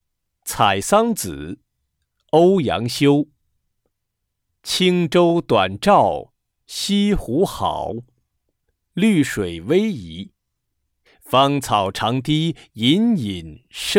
八年级语第六单元 课外古诗词诵读《采桑子》课文朗读素材